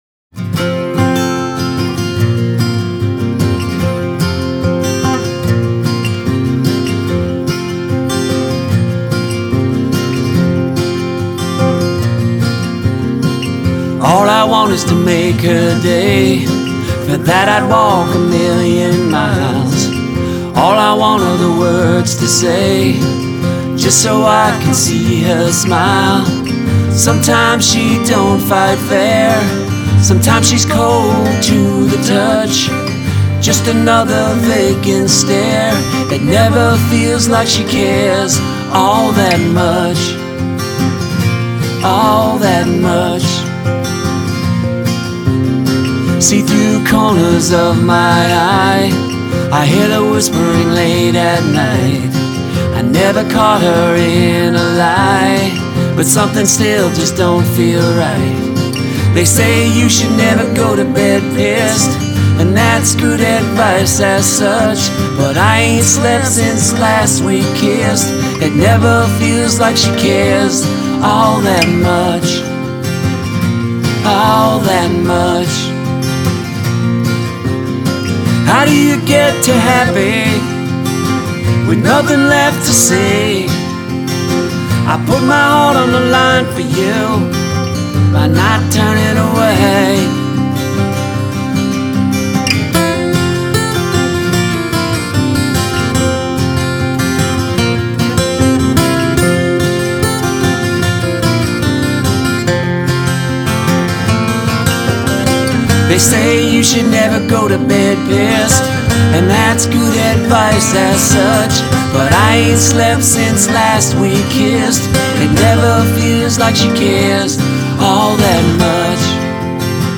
vocals, guitar